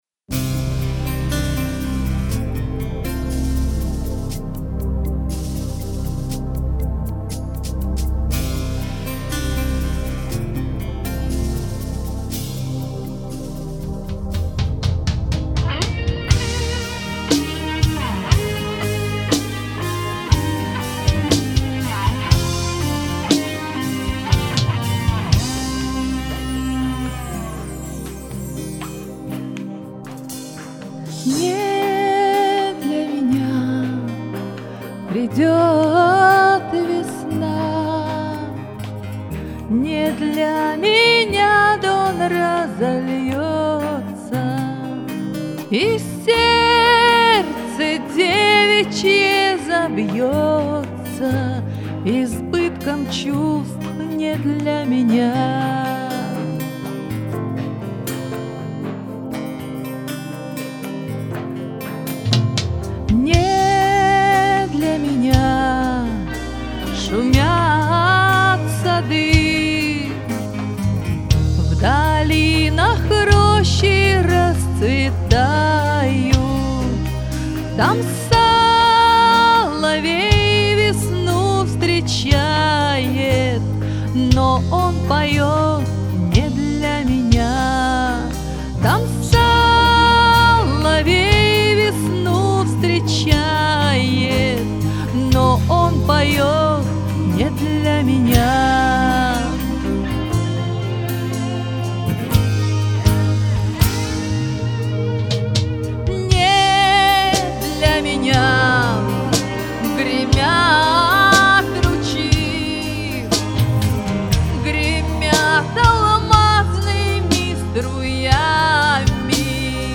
Запись, демо, не отработана по записи и вокально.